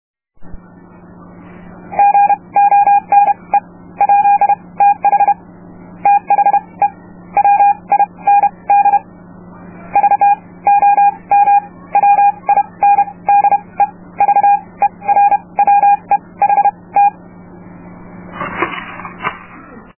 example for morse-code with a touchkeyer